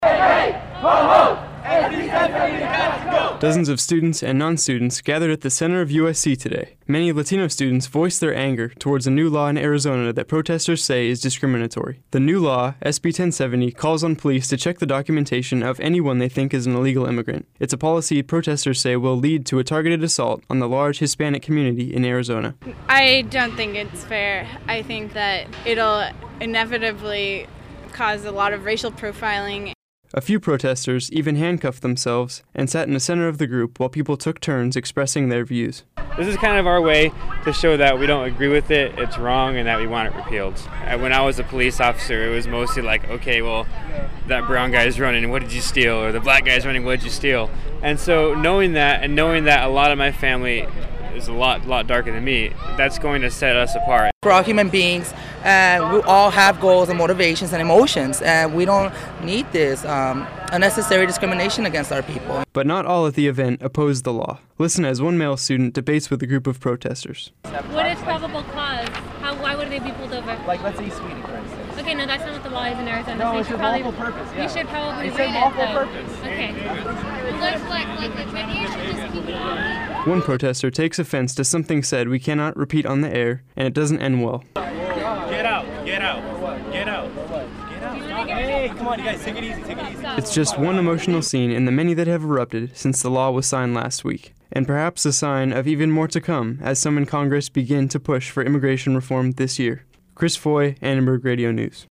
Dozens of students and non-students gathered at the center of the USC today.
A few protestors even handcuffed themselves and sat in a center of the group while people took turns expressing their views.
Listen as one male student debates with a group of protestors.
One protestor takes offense to something said we cannot repeat on air and it doesnt end well.